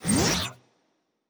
Sci-Fi Sounds / Electric / Device 1 Start.wav